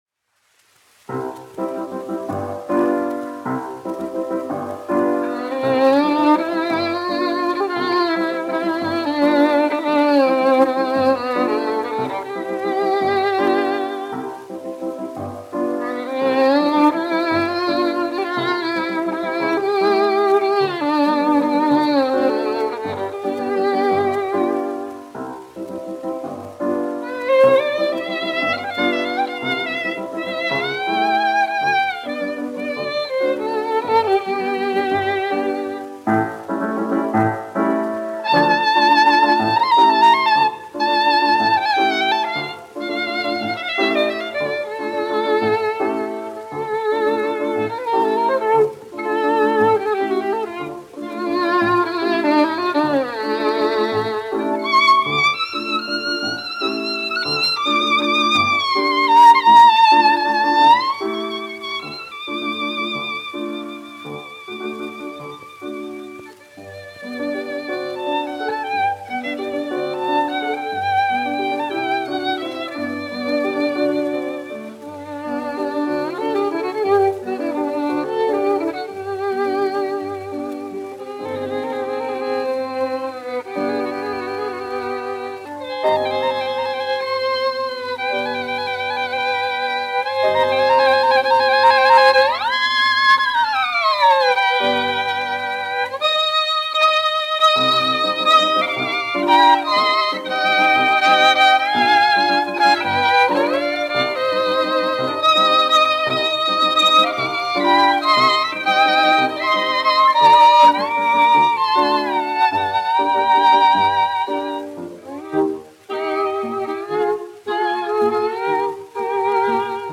1 skpl. : analogs, 78 apgr/min, mono ; 25 cm
Vijoles un klavieru mūzika
Latvijas vēsturiskie šellaka skaņuplašu ieraksti (Kolekcija)